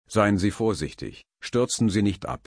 crashde.mp3